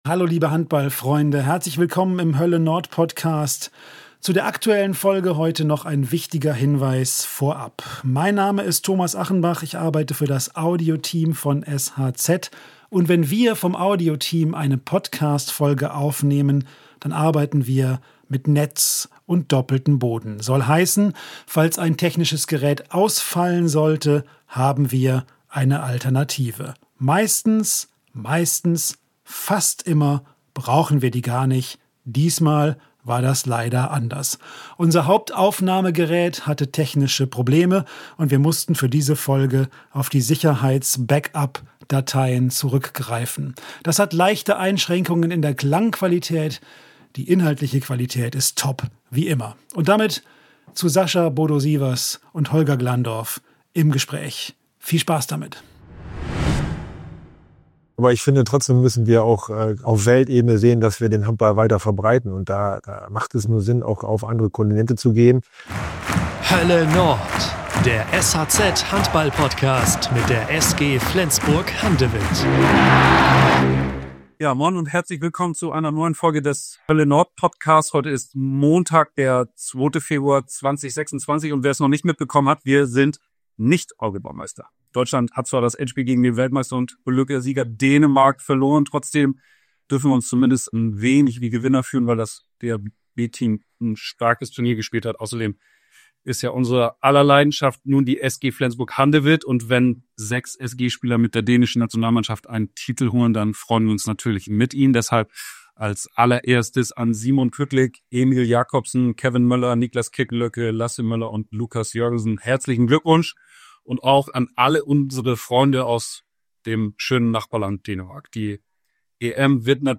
Fragen, die wir in der neuesten Folge des „Hölle Nord“-Podcasts Geschäftsführer Holger Glandorf gestellt haben.